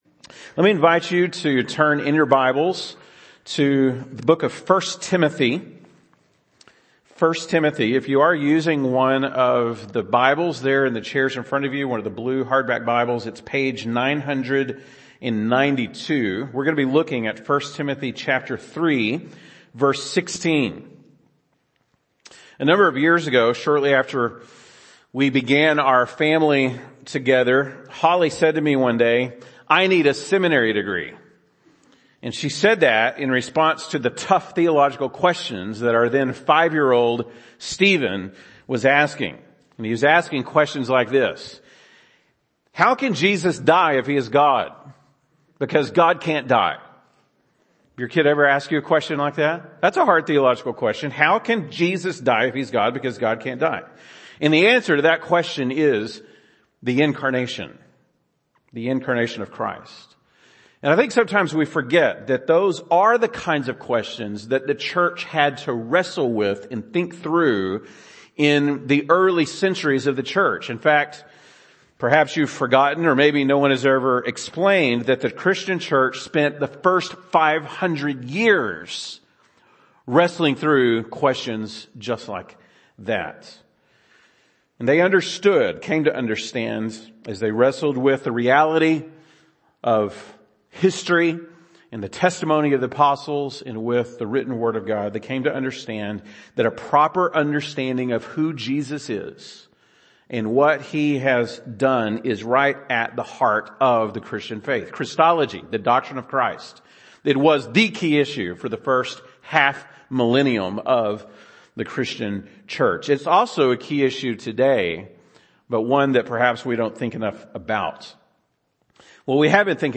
December 25, 2022 (Sunday Morning)